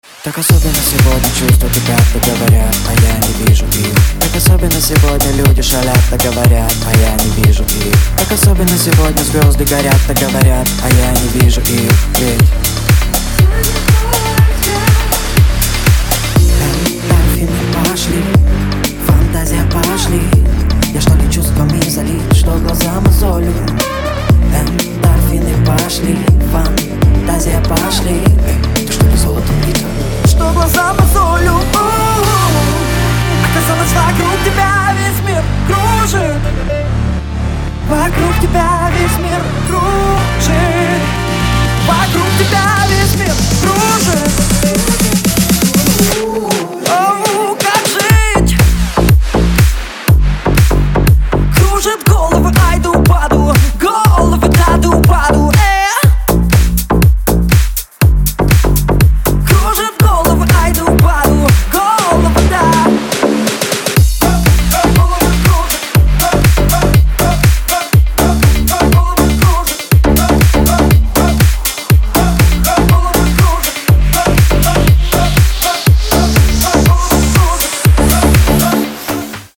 • Качество: 192, Stereo
мужской вокал
громкие
dance